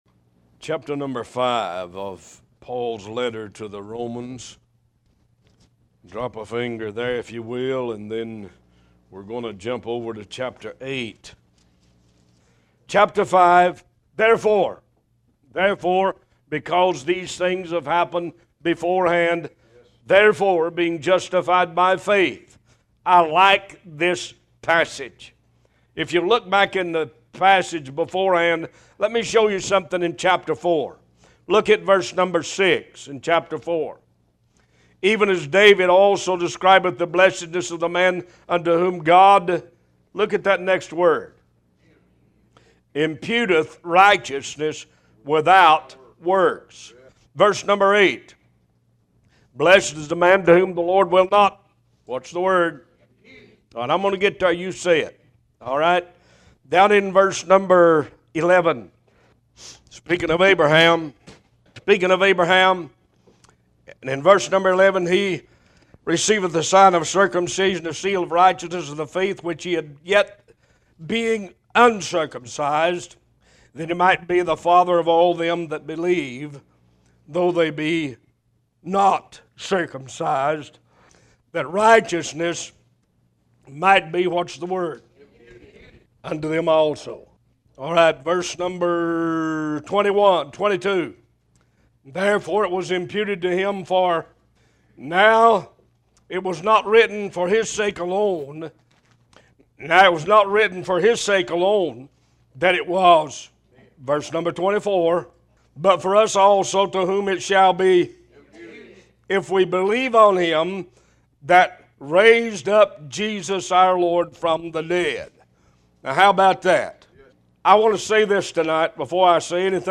One Voice Talk Show